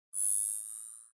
rangefinderzoomin.mp3